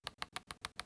SFX_Inv-Scrolling.wav